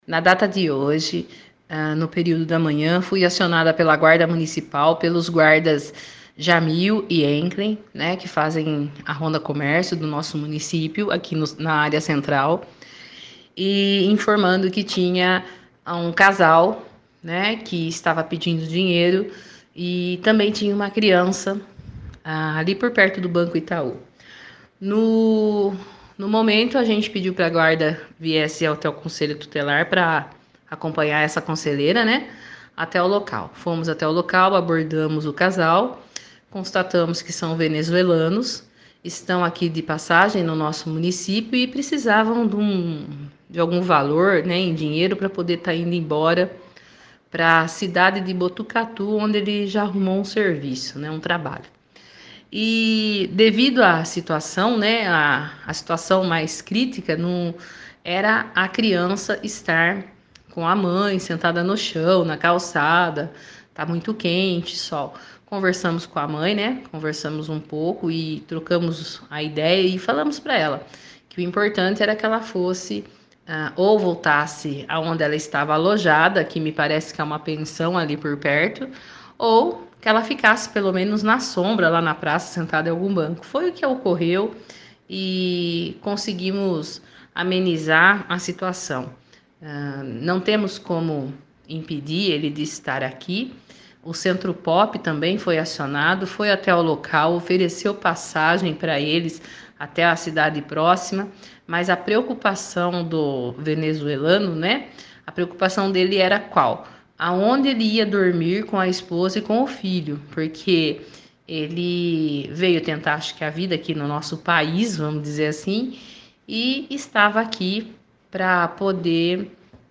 Nossa reportagem esteve conversando com a conselheira sobre essas duas situações, e, ela esclareceu quais foram os procedimentos e os motivos que levaram à tais medidas para cumprimento do ECA – Estatuto da Criança e do Adolescente.